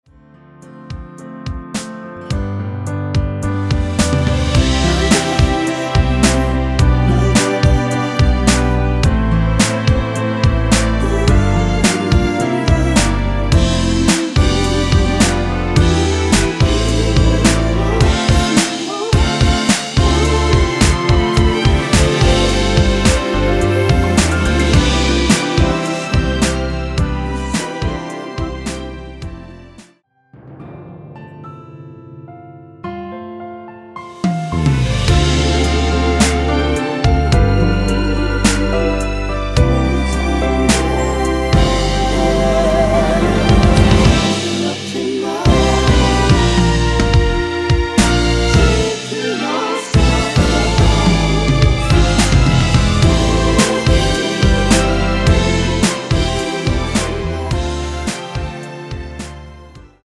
원키 코러스 포함된 MR입니다.
앞부분30초, 뒷부분30초씩 편집해서 올려 드리고 있습니다.